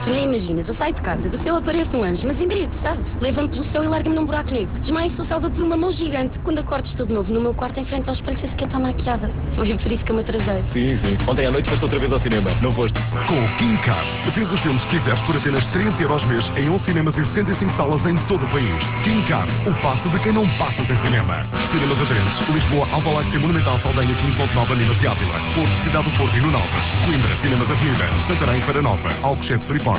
clique para ouvir o spot). Esta campanha passa na Mega FM e TSF.